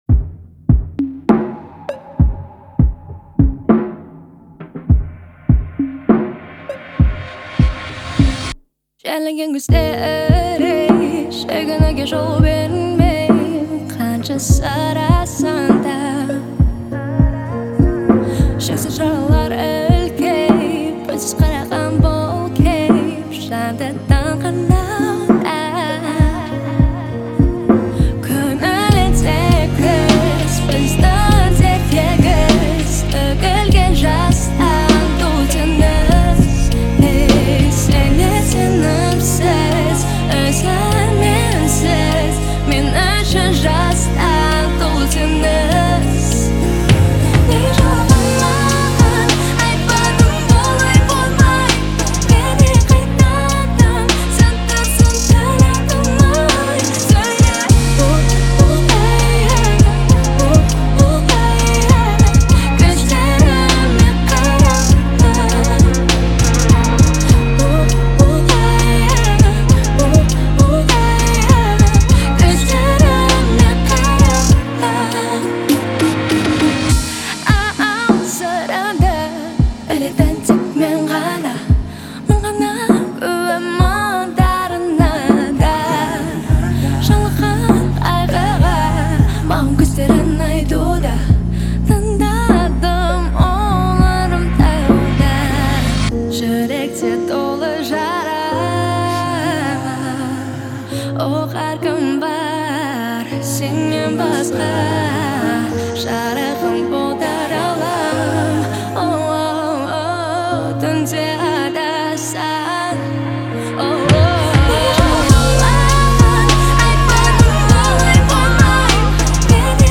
атмосферными инструментами и выразительным вокалом